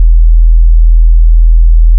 41 Hz Sinuston (entspricht dem E eines 4-Saiters ohne Obert�ne)
41hz-sine.wav